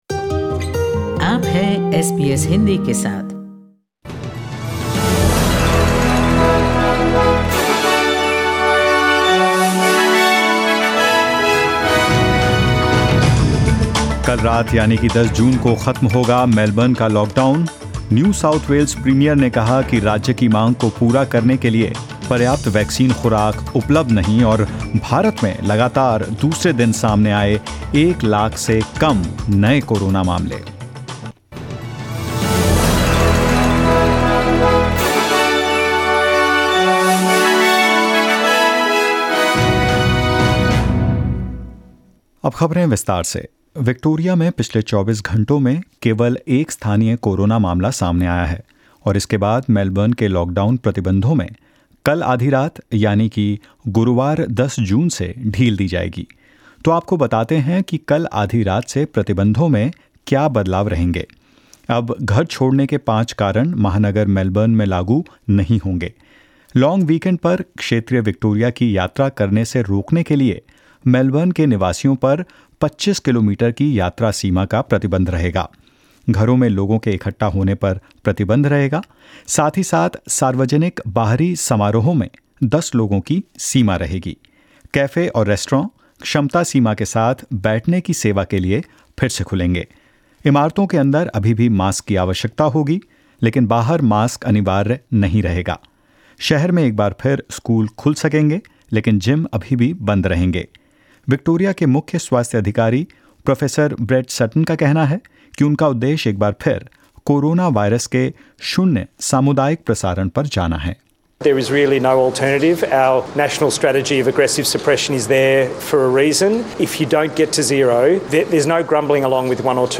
In this latest SBS Hindi News bulletin of Australia and India: Queensland records one community case of coronavirus; Melbourne to end its lockdown on Thursday midnight but some restrictions to remain in place; India records less than 100,00 Covid-19 cases for the second consecutive day and more.